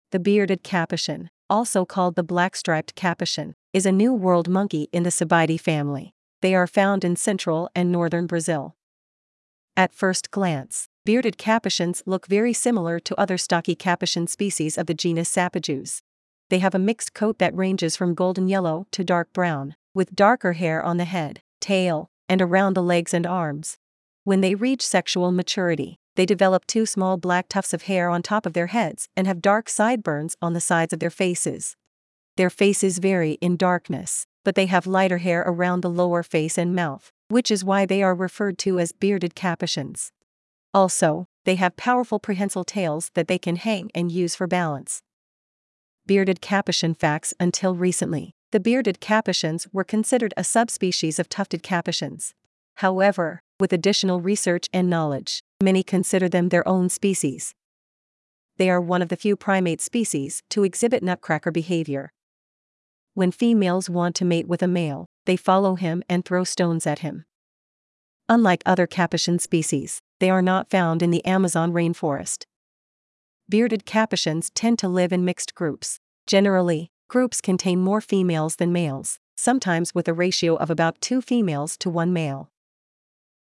Bearded Capuchin
Bearded-Capuchin.mp3